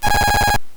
I used a simple voltage divider, 330 kΩ and 3.3 kΩ, to get the signal level down a bit. The attenuated signal, between the two resistors, is soldered to a 3.5 mm audio jack.
Just looking at the waveform, we can see a pattern: A lot of the time, the signal is high for a long-ish duration, followed by a short pause where it’s low.
Here’s the script running with !!headphone warning!!
this recording of a shock with an intensity of 7 on channel 1:
shock_07.wav